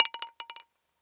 shell_casing.wav